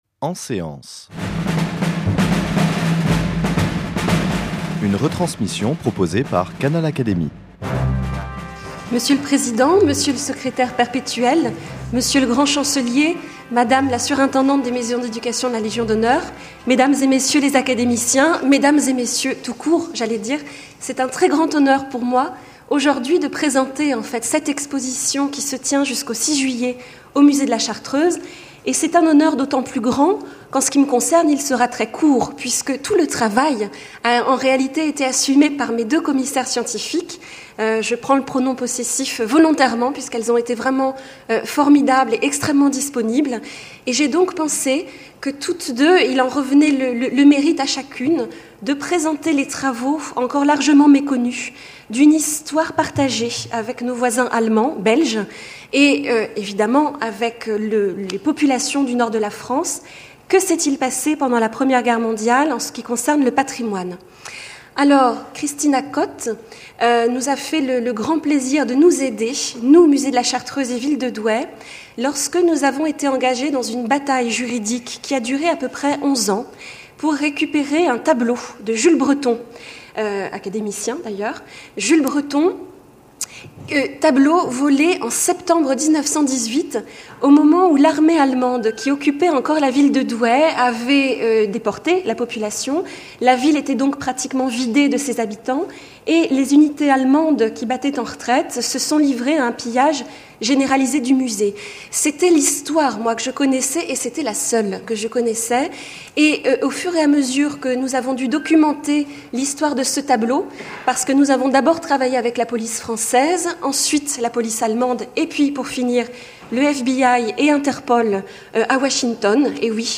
Cette séance a été enregistrée le 2 avril 2014. La séance est suivie des débats, contenu exclusif réservé au membres du Club Canal Académie.